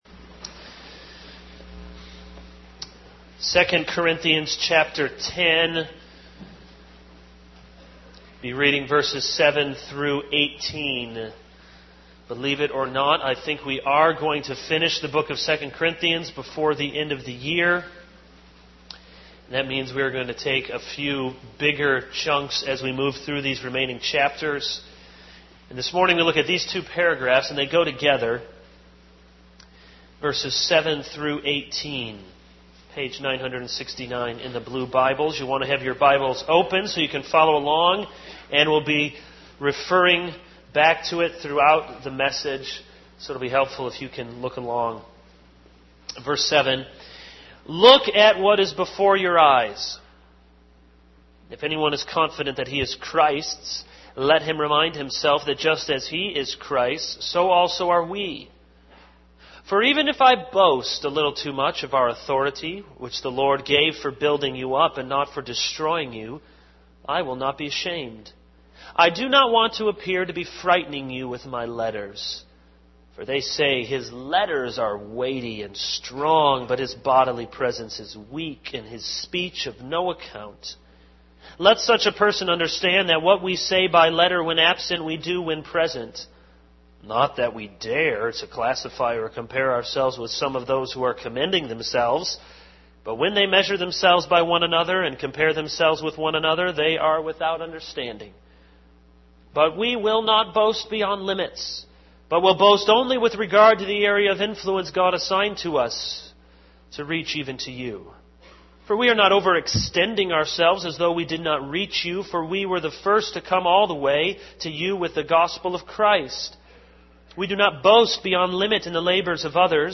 This is a sermon on 2 Corinthians 10:7-18.